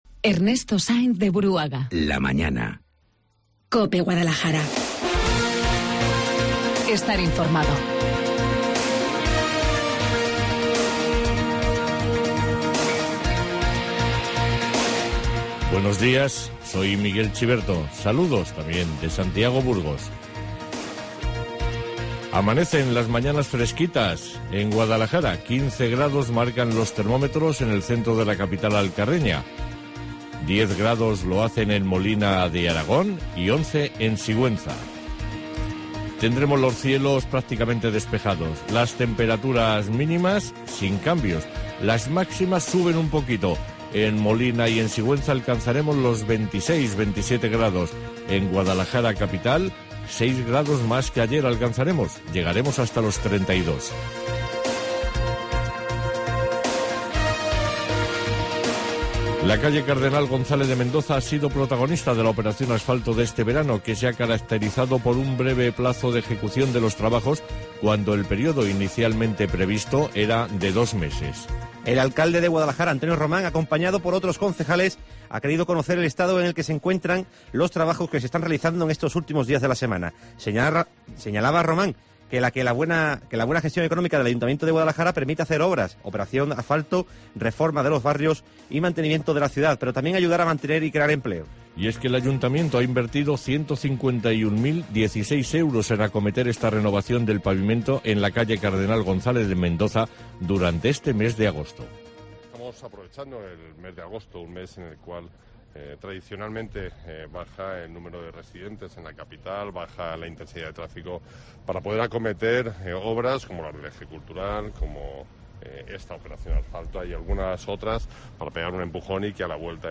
Informativo Guadalajara 9 de agosto